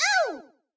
toadette_hurt_hard.ogg